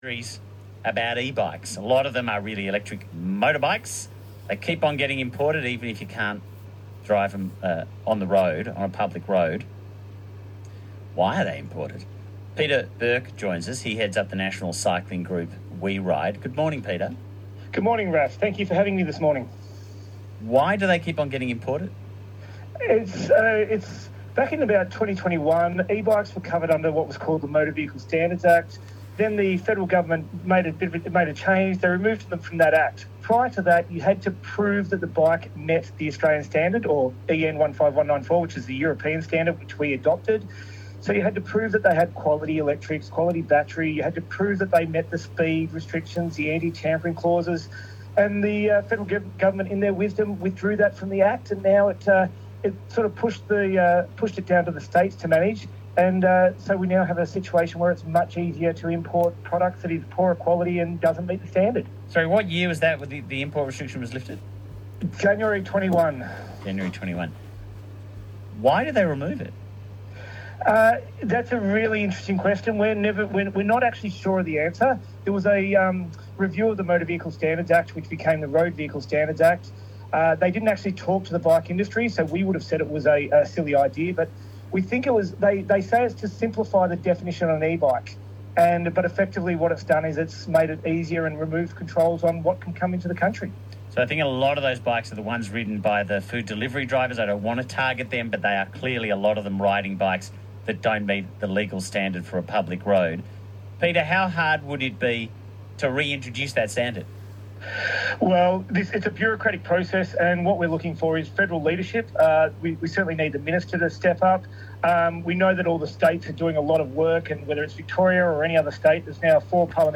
Updated 7 August: Media Interview